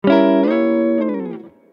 Wrong Answer.mp3